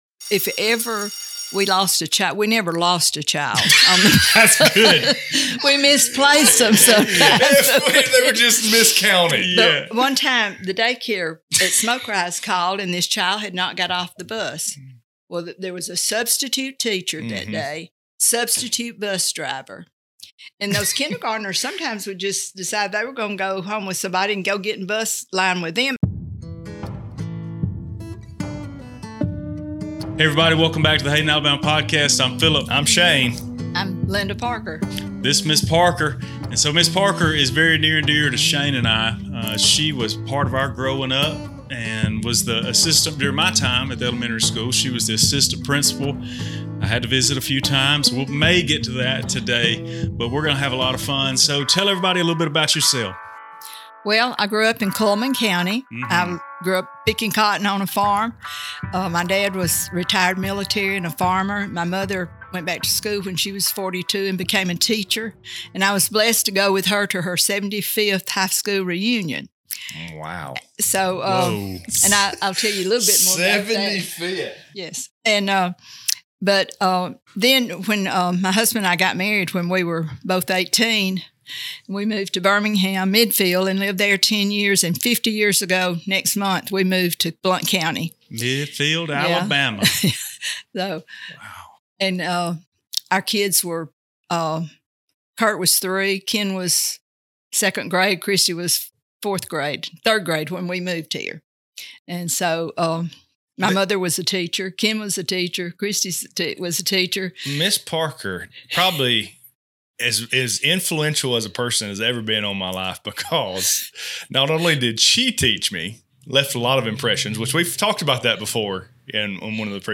Before we knew it, we were all laughing and sharing tales we hadn't thought about in years.